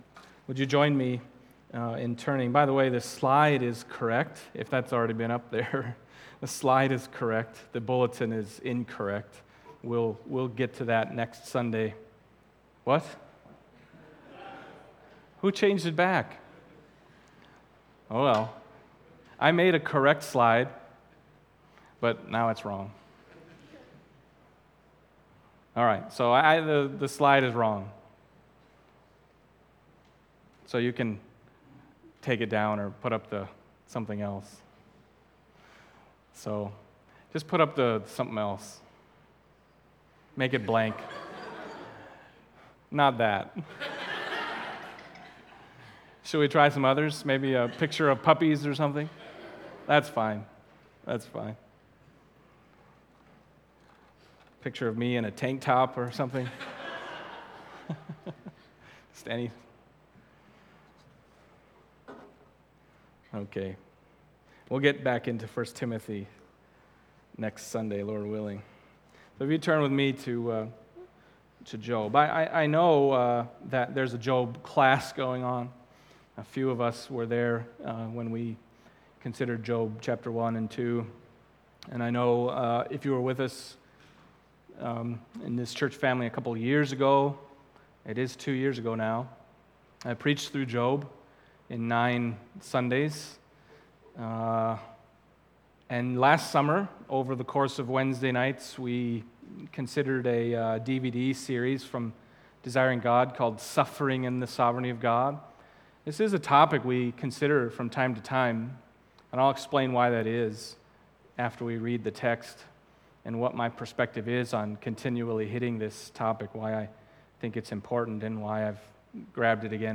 Other Passage: Job 1:1-2:10 Service Type: Sunday Morning Job 1:1-2:10 « The Son